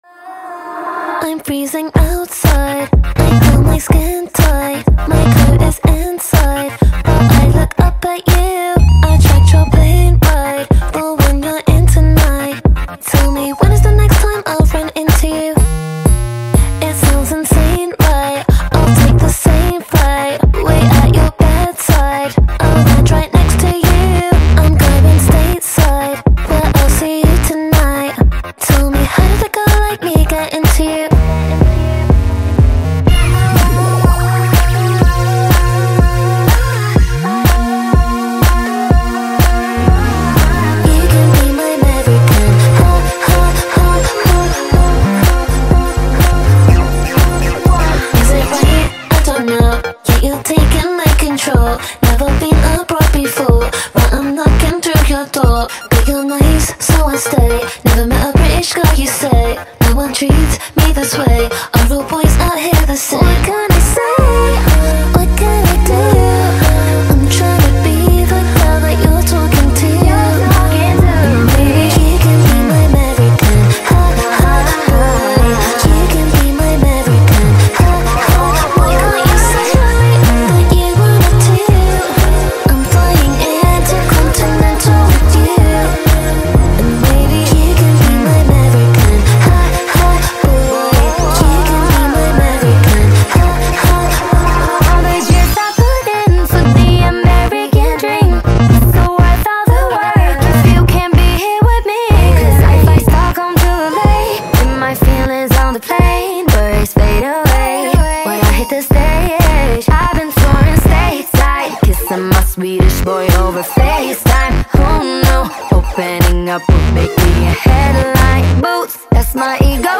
Award winning singer-songsmith